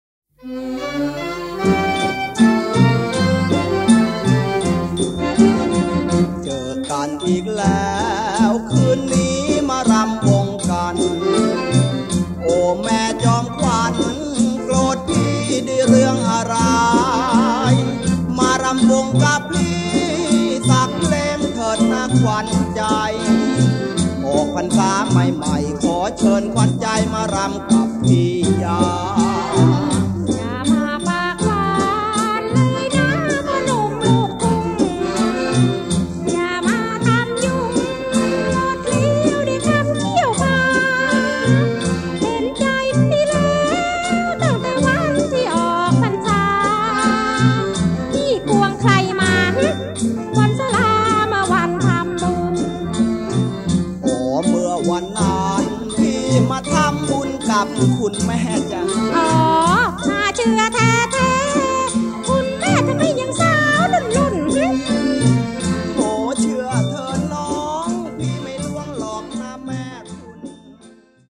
ร้องคู่